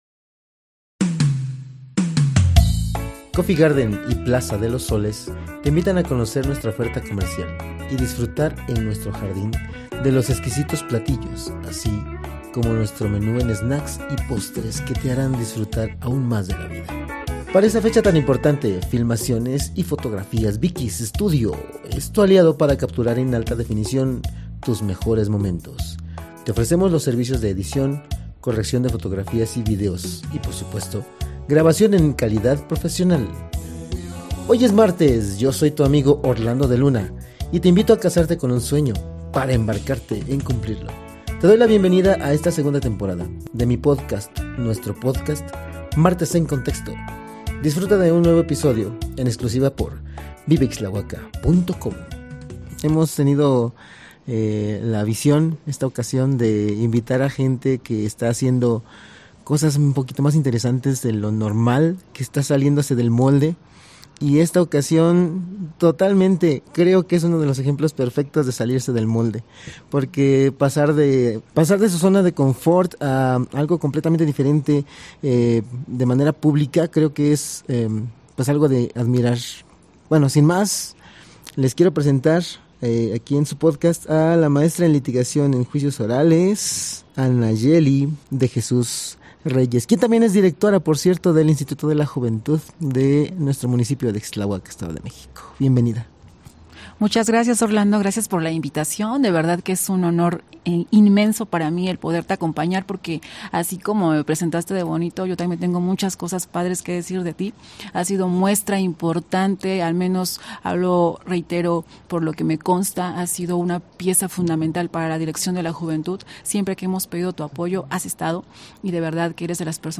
Locación: Cafetería “Coffee Garden".